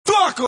Tm8_Chant42.wav